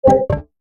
UI_SFX_Pack_61_52.wav